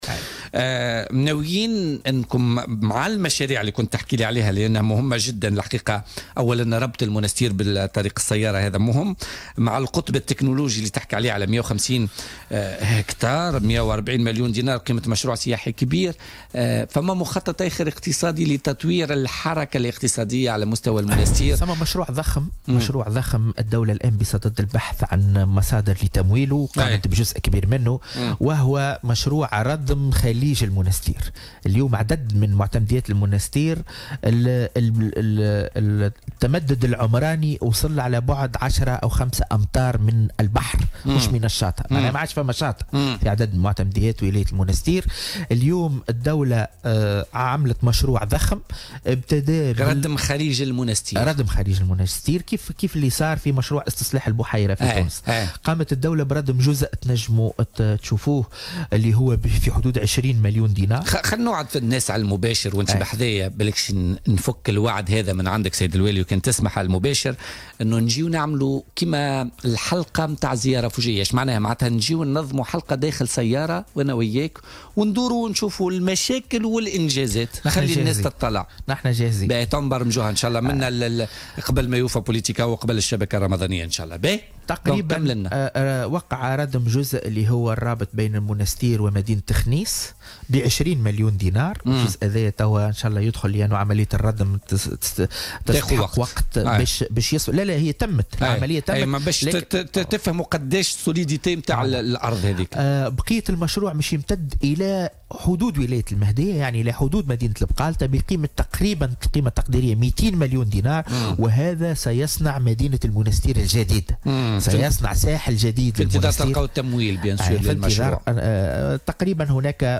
وقال السبري، ضيف بوليتكا، اليوم الأربعاء، إن الدولة انطلقت بردم الجزء الرابط بين مدينتي خنيس والمنستير بكلفة 20 مليون دينار، في انتظار استكمال المشروع ليشمل كامل خليج المنستير إلى حدود مدينة بقالطة قرب حدود ولاية المهدية، بقيمة جملية ستبلغ 200 مليون دينار، وهو ما سيصنع "مدينة المنستير الجديدة" حسب تعبيره.